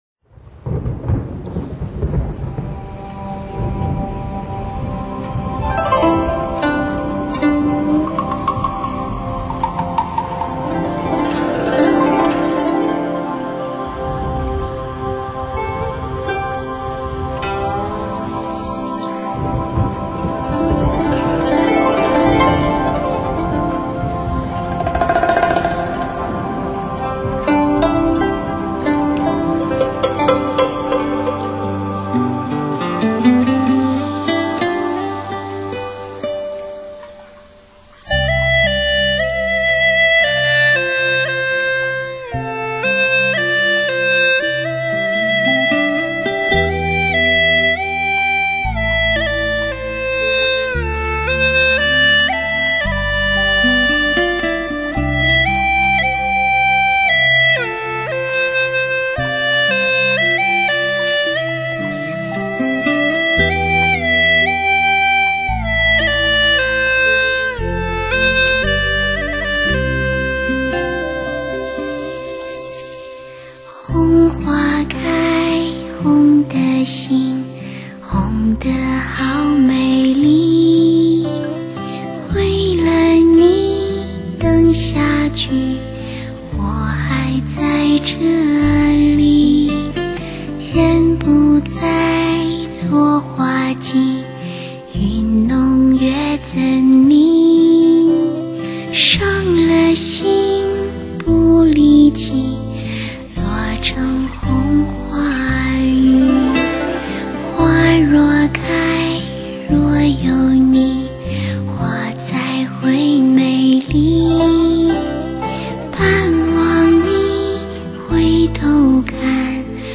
所属分类：音乐:发烧/试音:流行
让优美的歌声伴随你，专为汽车音响量身定做的HI-FI唱片，令你在飞驰中享受近乎无暇的美妙音乐。